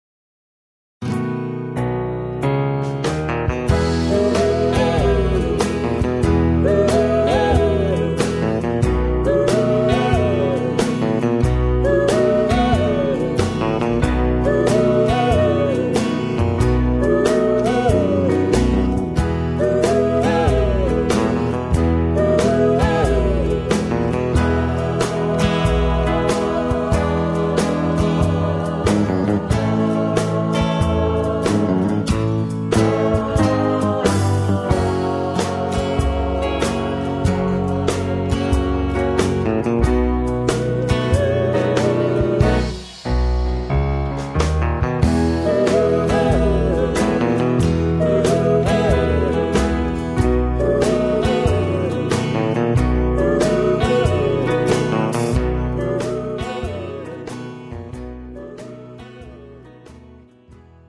MR 고음질 반주 다운로드.